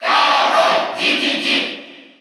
Category: Crowd cheers (SSBU) You cannot overwrite this file.
King_Dedede_Cheer_Russian_SSBU.ogg.mp3